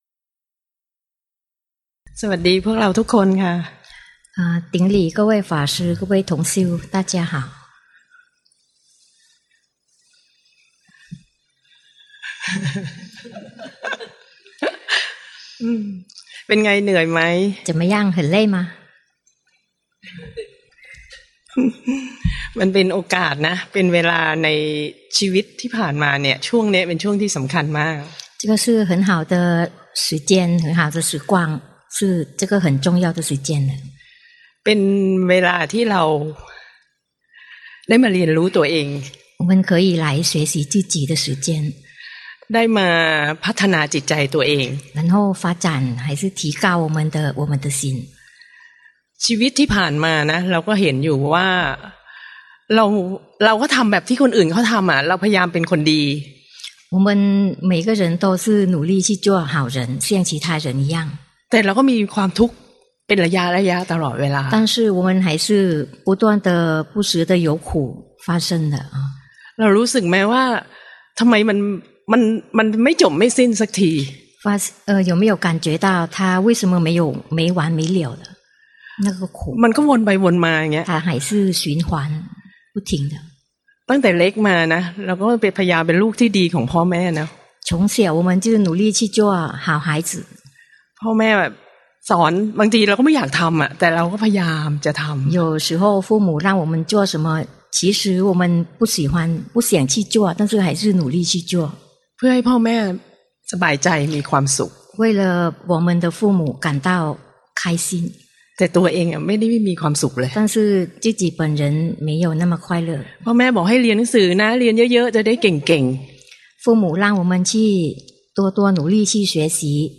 第十一屆泰國四念處禪修課程 課程現場翻譯